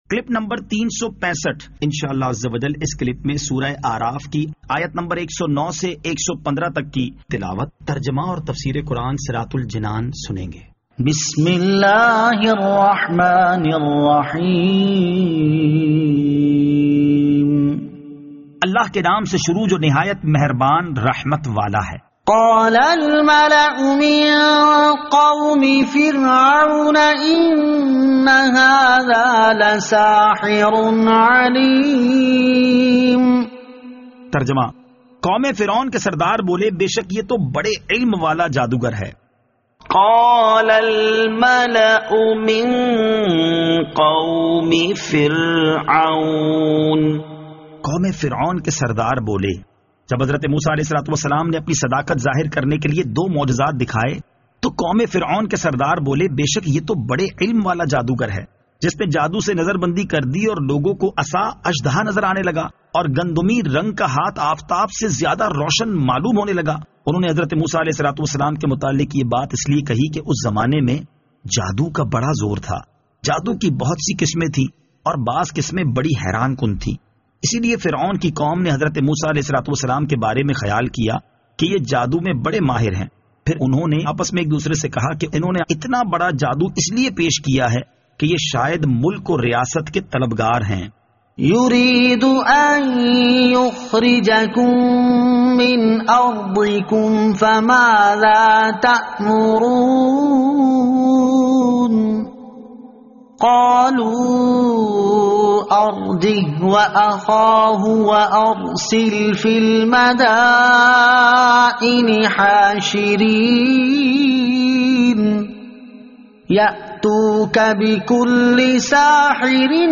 Surah Al-A'raf Ayat 109 To 115 Tilawat , Tarjama , Tafseer